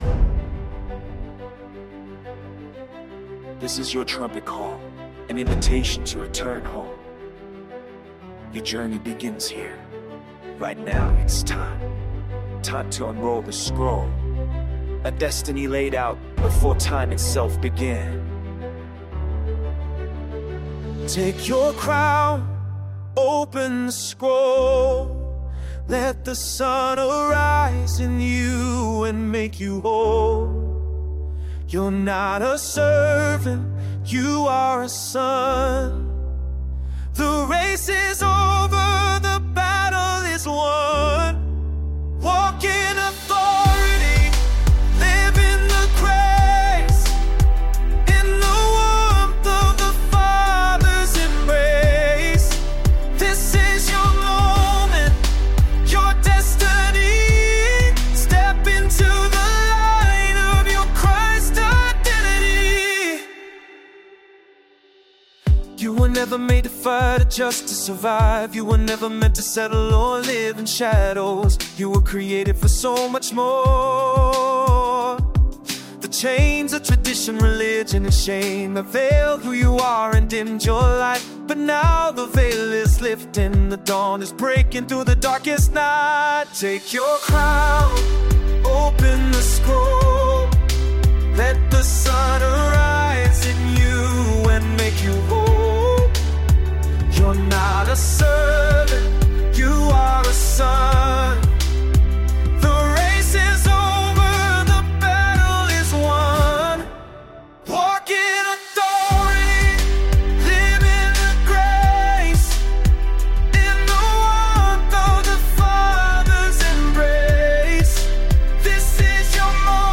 Trumpet+Call+(Spaced+&+Melodic)+(Replaced).mp3